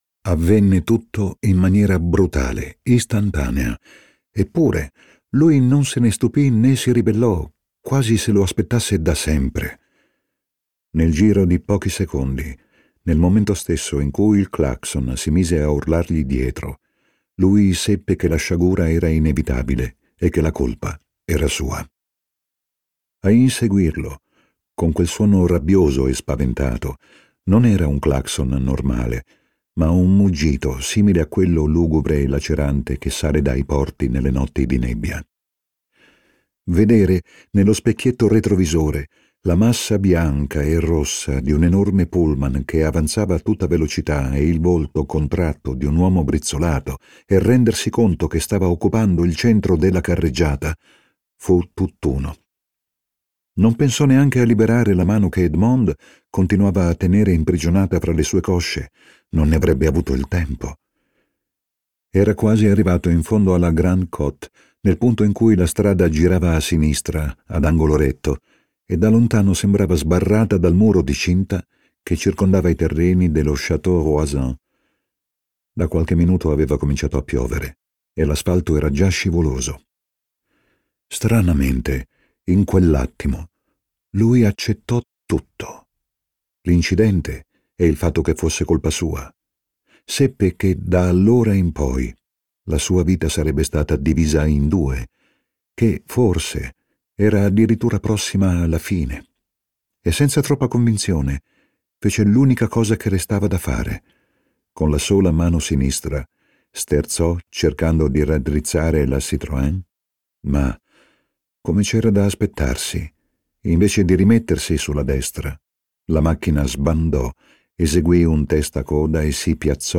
letto da Tommaso Ragno
Versione audiolibro integrale